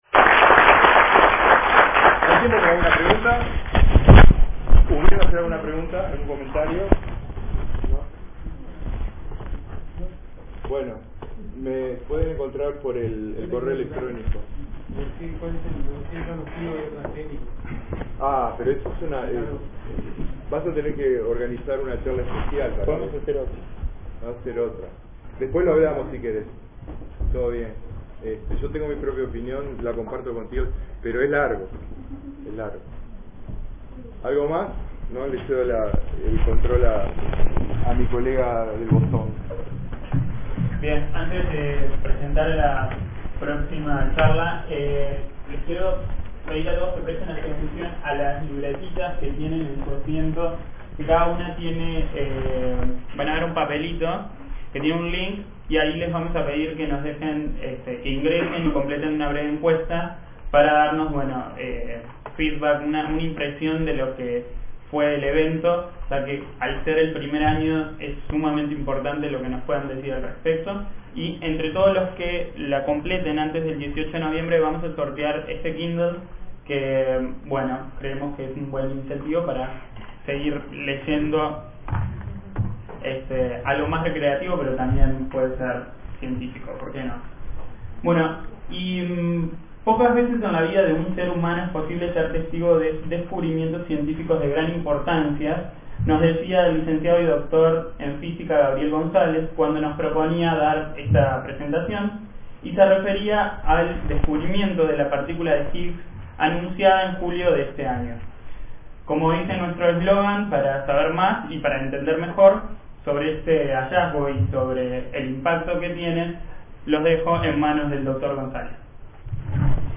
Charla El Higgs ha llegado, 6 de octubre 2012, Soc. Urug. de Astronom�a